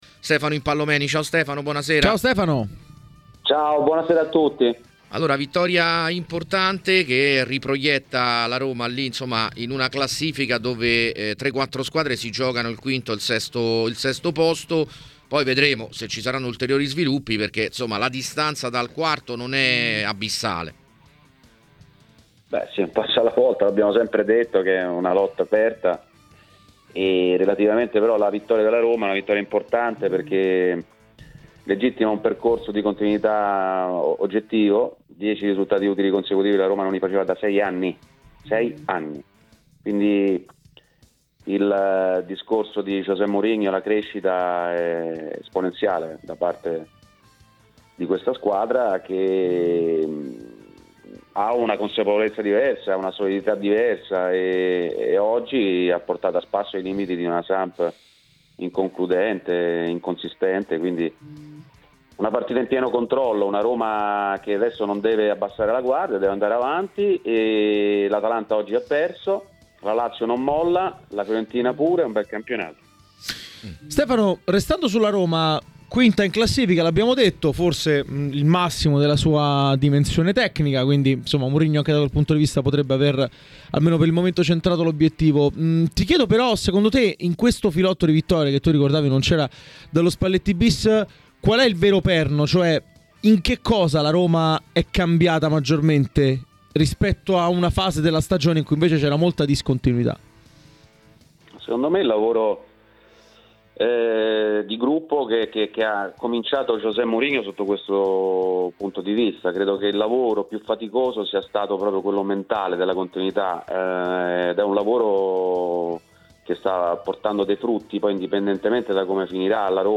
Durante la trasmissione Domenica Live a TMW Radio è intervenuto Stefano Impallomeni, ex calciatore, per parlare dei temi di giornata.